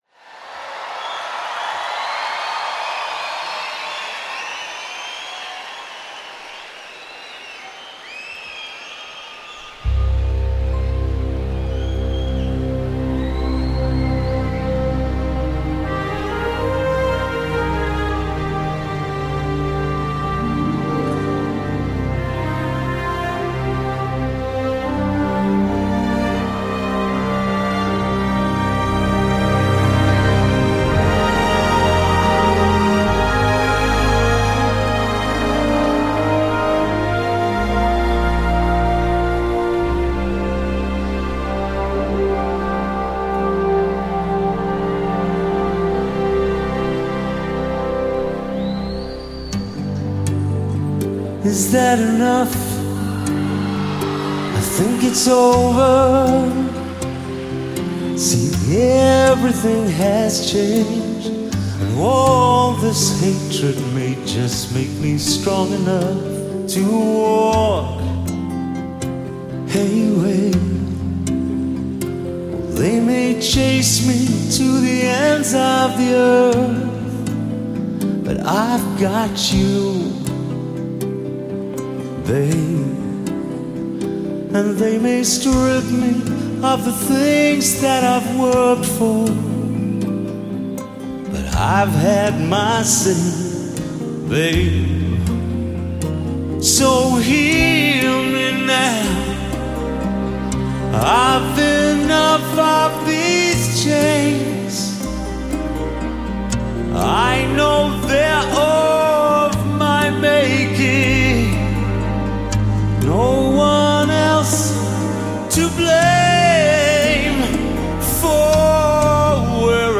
嗓音还是犹如清泉一样纯净，毫无杂质的黄金靓嗓